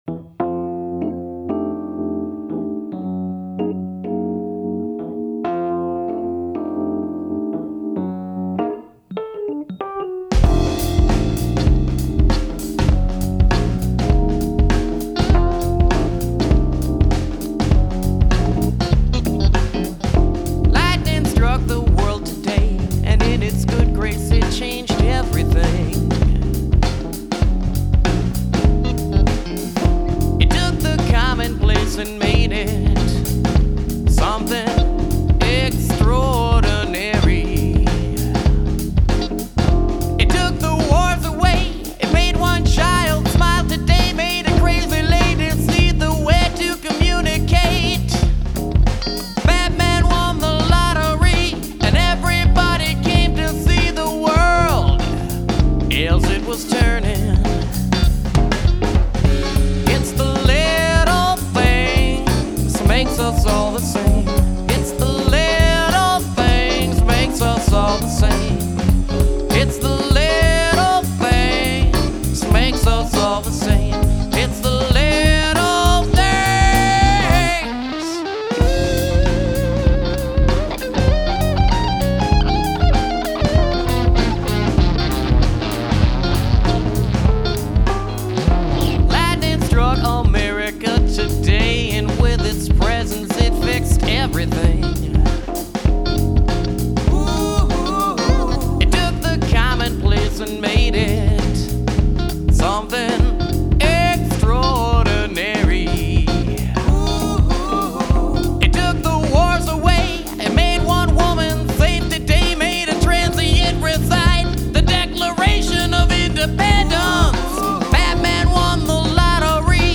Genre: Alternative power pop.